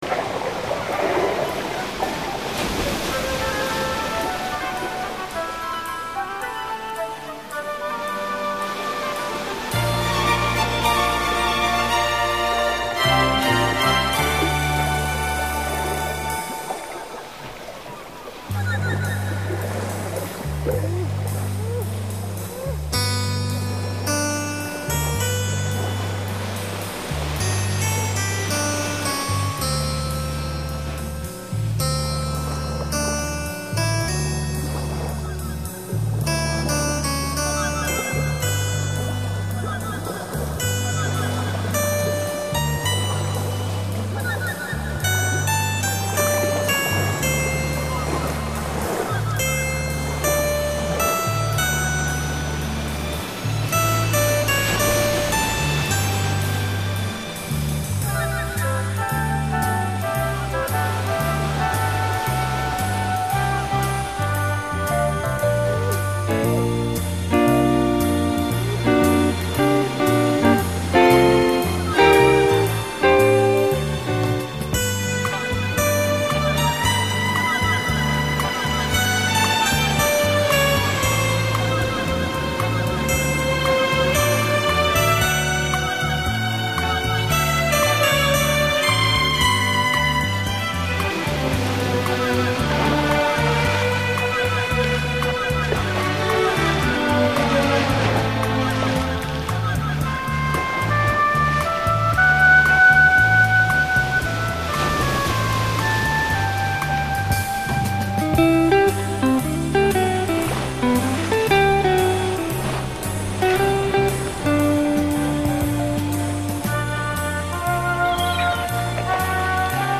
BEST OF THE SOUNDS OF NATURE